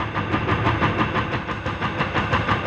RI_DelayStack_90-08.wav